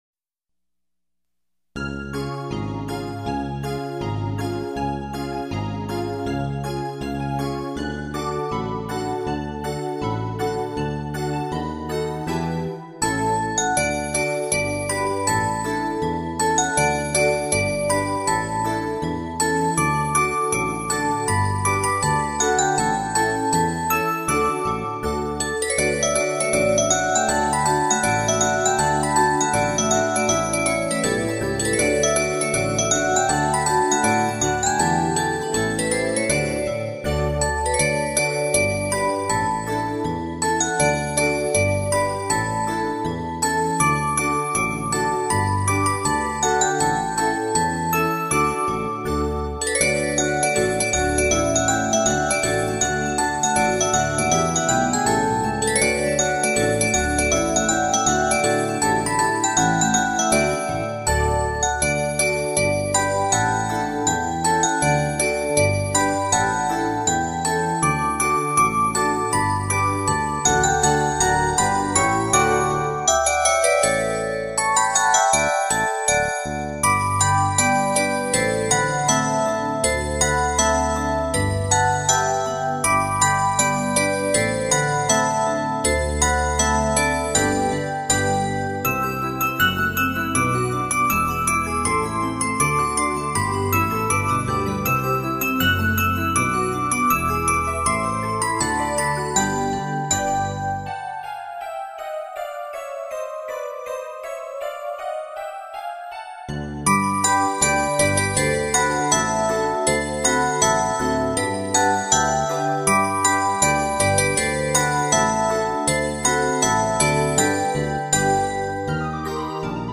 晶莹剔透的舒畅感，更是令人心醉，
水晶音乐，带给您无限遐思......